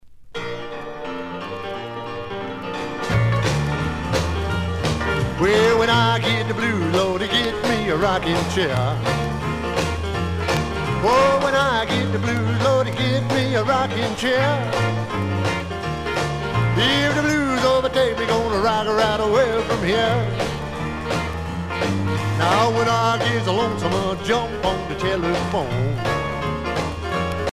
danse : rock